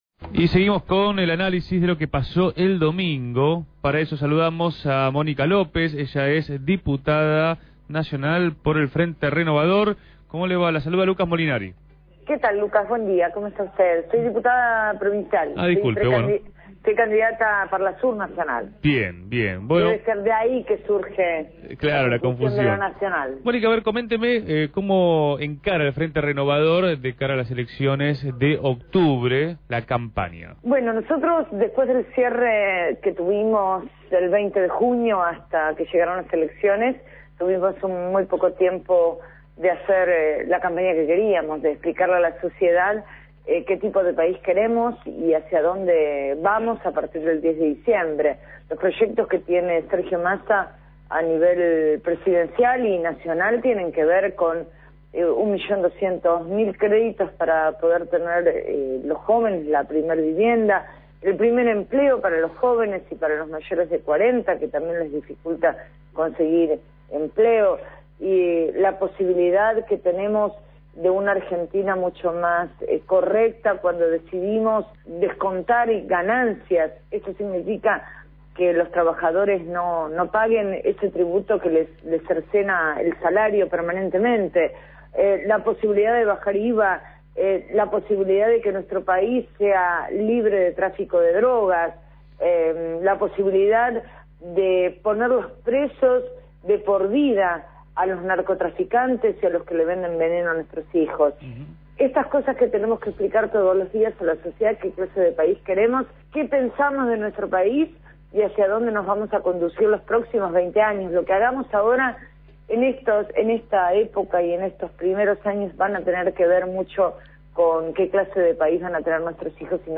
Tras las PASO nacionales llevadas acabo el 9 de Agosto, Mónica López, diputada provincial del Frente Renovador, fue entrevistada en Punto de Partida.
12-8-15-Entrevista-a-Monica-Lopez.mp3